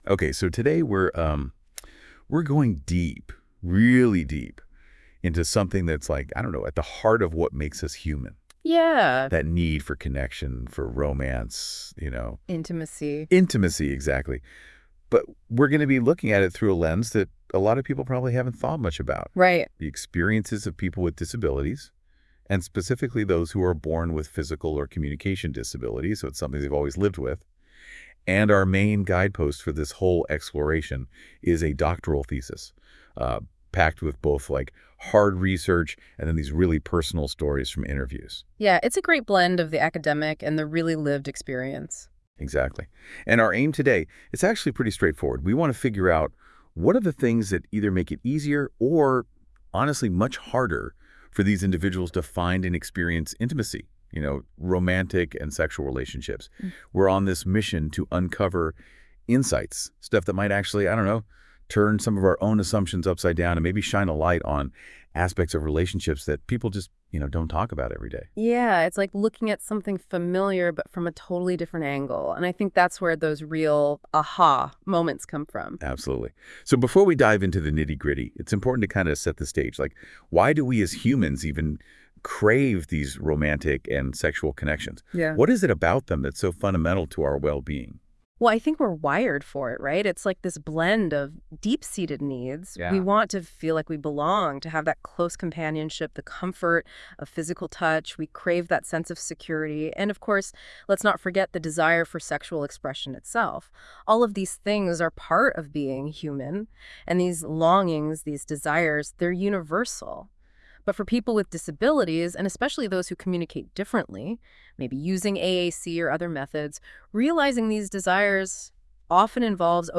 Yep, I’ve turned my research into a podcast-style conversation, and it’s ready for your ears.
NotebookLM didn’t just summarise my thesis—it created an engaging podcast-style audio conversation about it. Imagine two virtual voices casually dissecting years of research like they’re chatting over coffee.